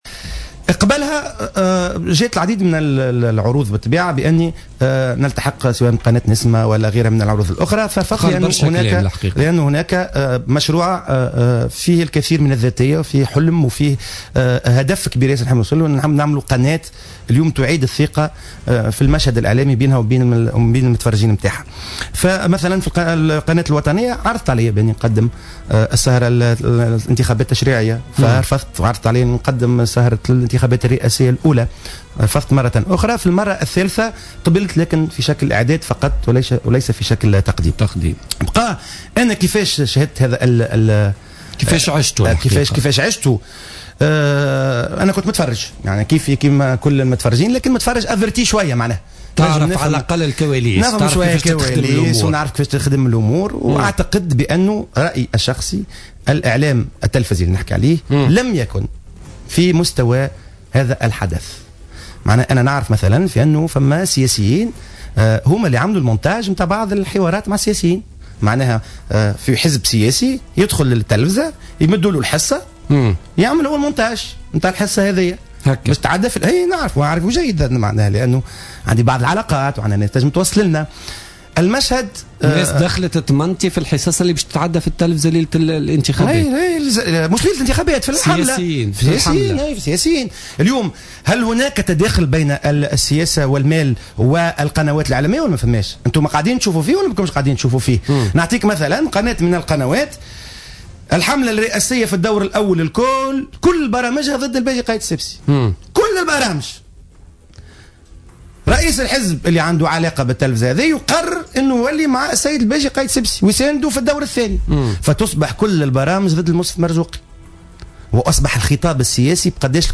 Tunisie 08/01/2015 à 14:49 Tweeter Exclusif: Moez Ben Gharbia dévoile les raisons de son départ d’Ettounsya TV L’animateur TV Moez Ben Gharbia était l’invité de Zouhaer Eljiss dans Politica du jeudi 8 janvier 2015. Dans son intervention, Moez Ben Gharbia est revenu sur les raisons de sa démission de la chaine télévisée privée Ettounsya TV.